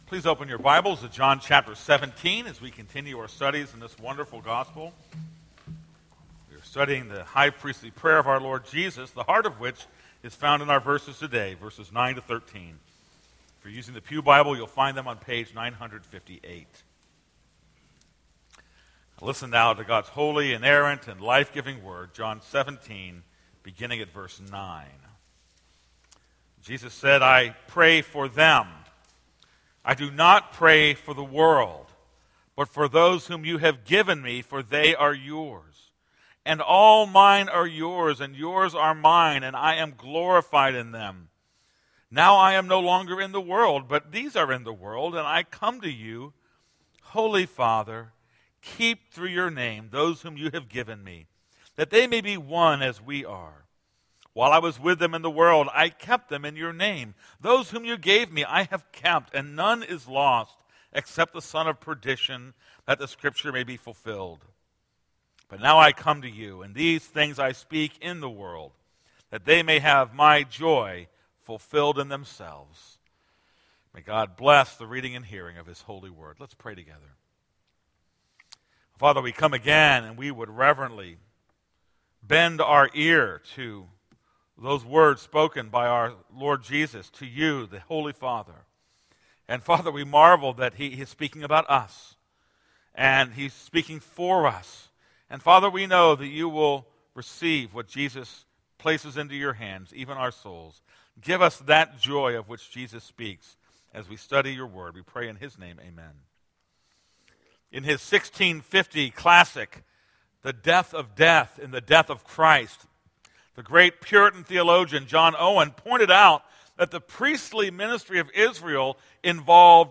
This is a sermon on John 17:9-13.